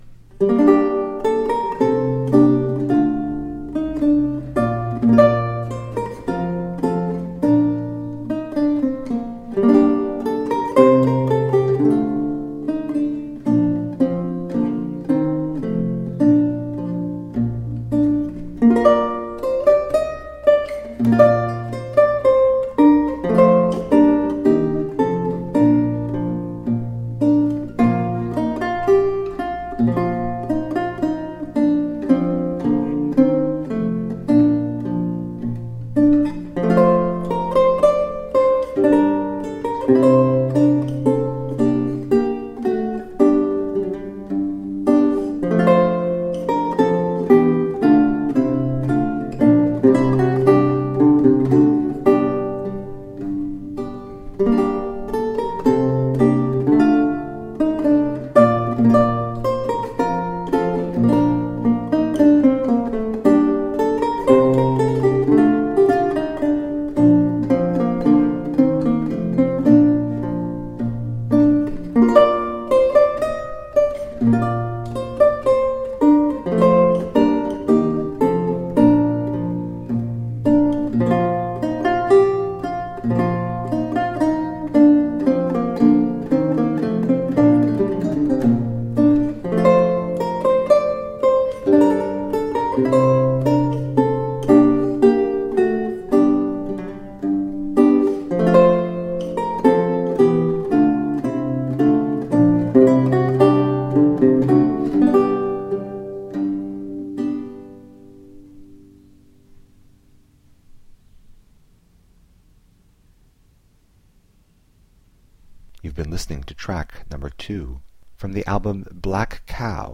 Renaissance lute.
Recorded 15-16 December 1998 in Wiltshire, UK
Classical, Renaissance, Instrumental